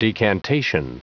Prononciation du mot decantation en anglais (fichier audio)
Prononciation du mot : decantation